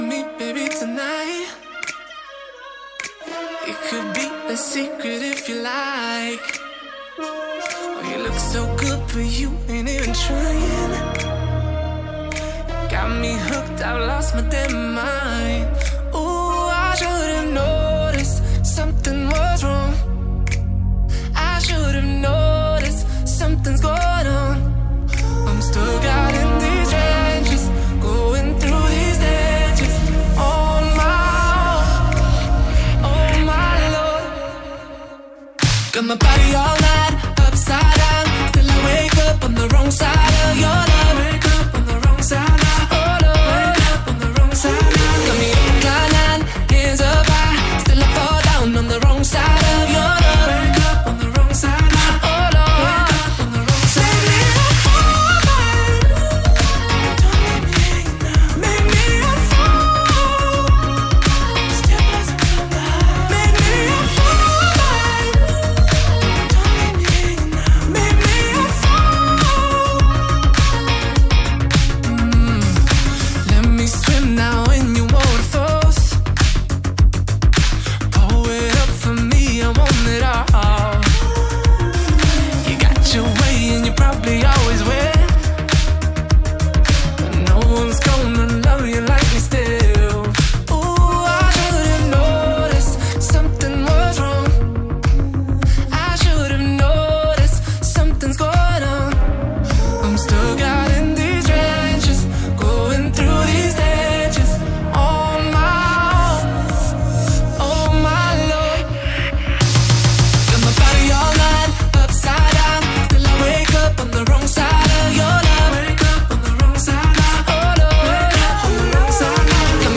Моя запись с онлайн Радио 107 Краснодар